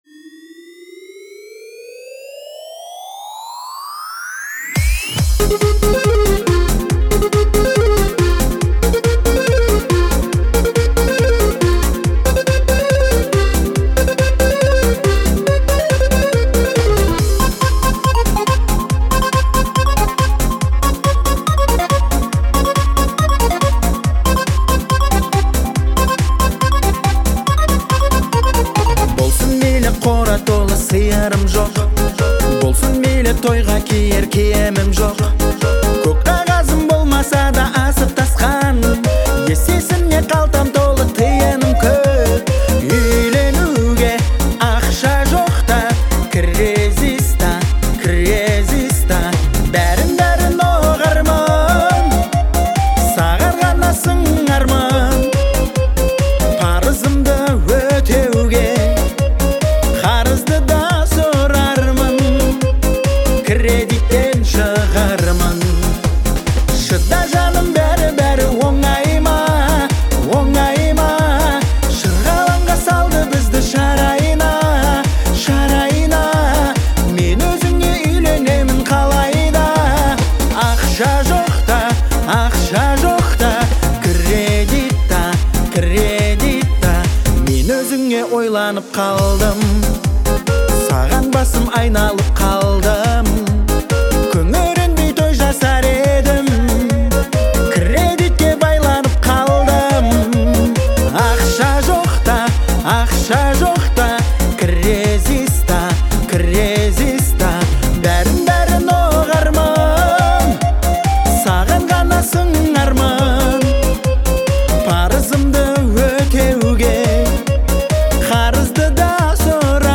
представляет собой увлекательное смешение поп и рэп жанров